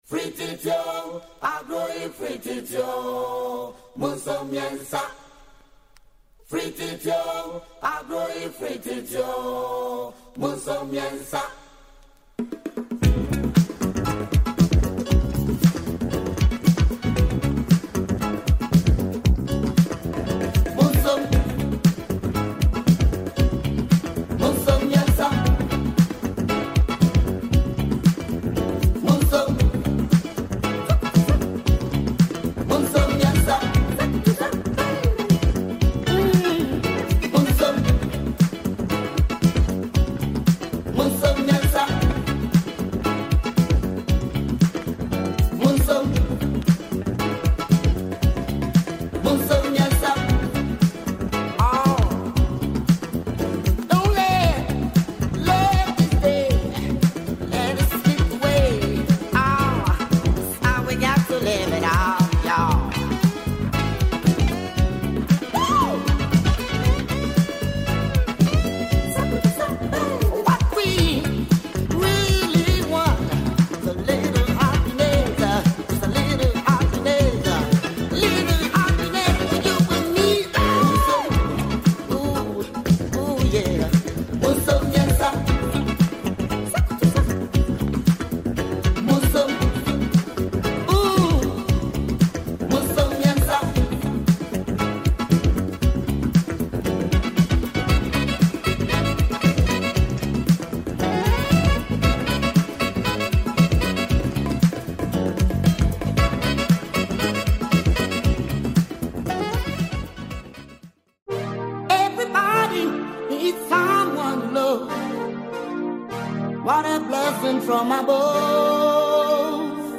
Mid 80's modern afro